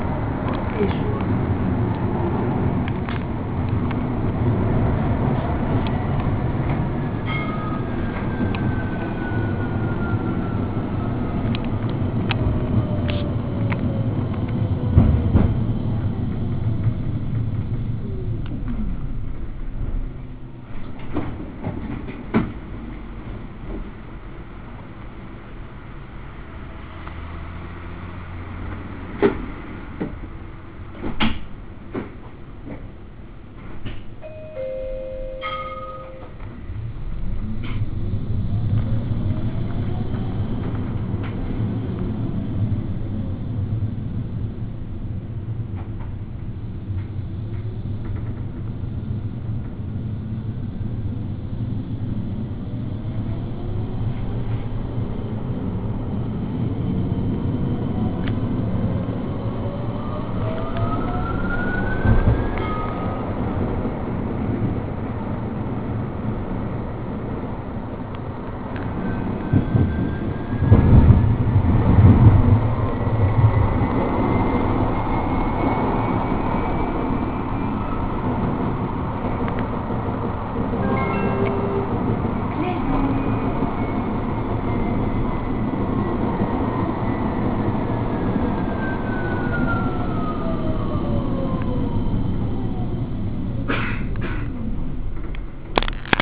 Vous pouvez eussi écouter une bande son enregistrée dans le tram même, sur la ligne 3 vers Sillon de Bretagne!
0"01" : annonce de l'arrêt Beauséjour
0"20" : ouverture manuelle des portes (un bouton est disposé sur chaque porte; le client qui décide de monter ou descendre appuie dessus)
0"29" : fermeture automatique des portes
0"34" : cloche signalant au conducteur que toutes les portes sont fermées
0"35" : autre cloche, mais extérieure, équivaut à un klaxon autorisé afin de prévenir les piétons et voitures du passage du tram
plusieurs accélérations...
1"02" : rebelote, la cloche extérieure
1"20" : annonce du prochain arrêt : "Plaisance", puis fin d'accélération (équivalent à un point mort, le tram subit son élan)
1"25" : début du freinage du tram
1"33" : arrêt à la station Plaisance